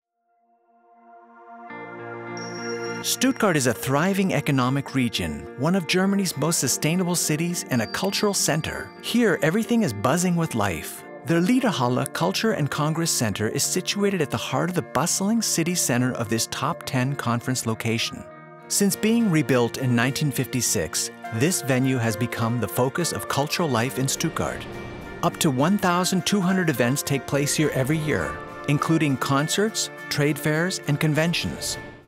US-Amerikaner, wohnhaft in Deutschland, English Native Speaker, Image-Filme, Erklär-Videos, spreche auch Deutsh
mid-atlantic
Sprechprobe: Industrie (Muttersprache):